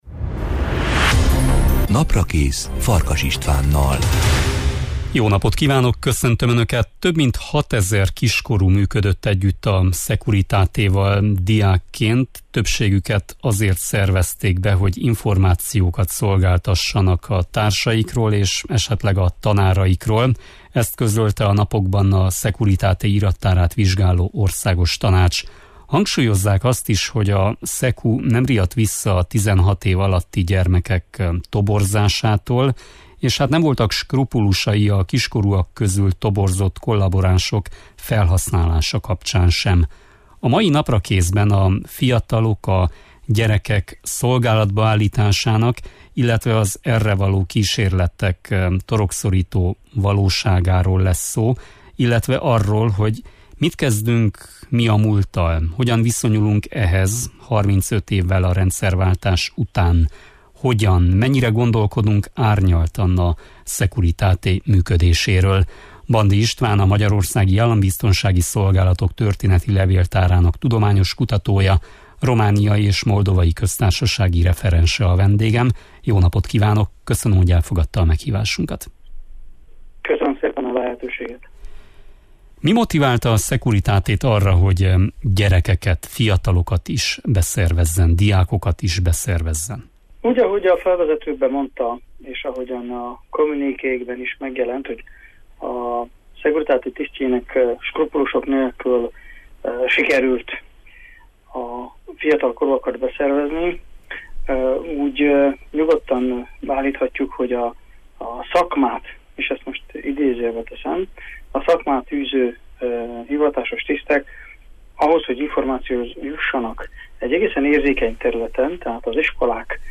A soron következő Naprakészben a fiatalok, gyerekek „szolgálatba állításának”, illetve az erre való kísérletek torokszorító valóságáról beszélgetünk.